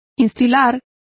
Complete with pronunciation of the translation of instil.